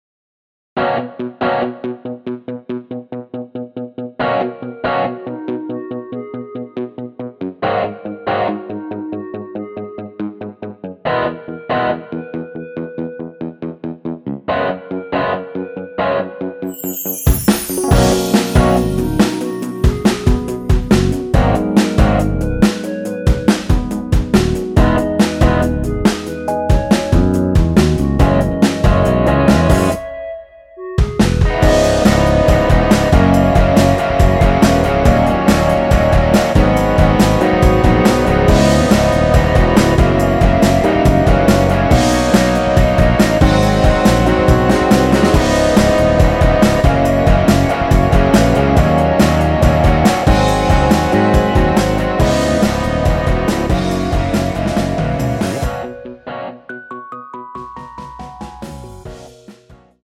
원키에서(-3) 내린 멜로디 포함된 MR 입니다.(미리듣기 참조)
앞부분30초, 뒷부분30초씩 편집해서 올려 드리고 있습니다.
중간에 음이 끈어지고 다시 나오는 이유는